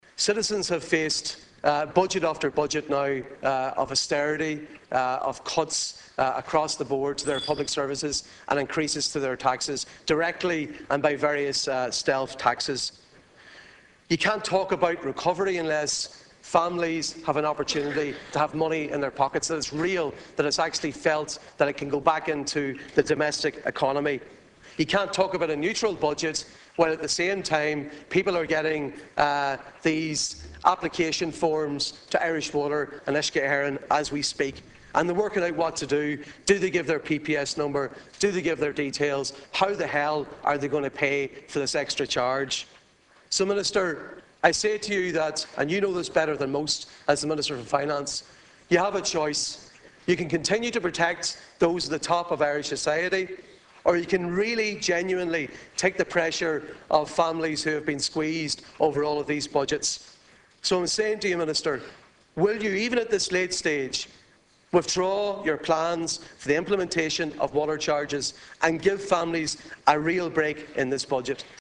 The call was made in the Dail by Donegal Deputy Padraig MacLochlainn.
Despite appearing to find the comments amusing himself, Deputy MacLaughlin said the issue was no laughing matter: